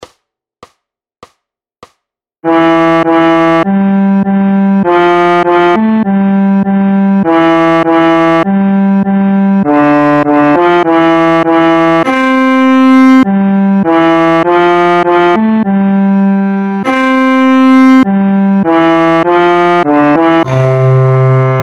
Noty na violoncello.
Hudební žánr Vánoční písně, koledy